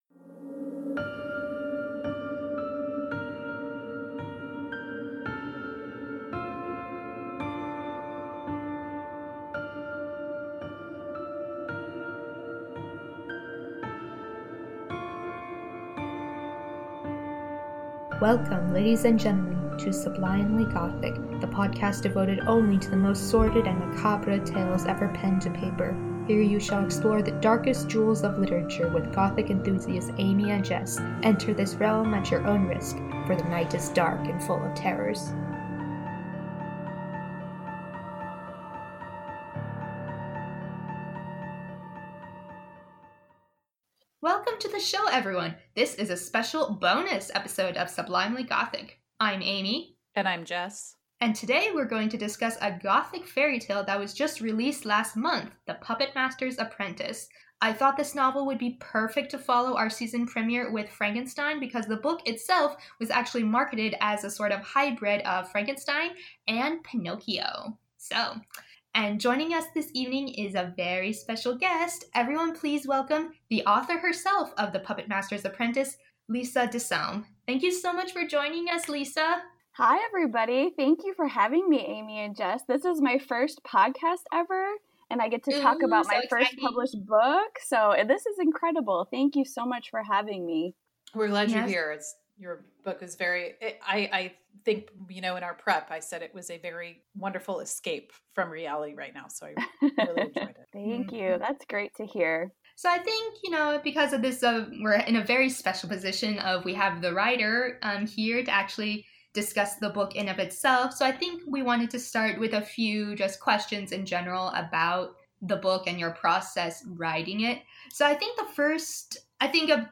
Spoiler-free interview until 27:12!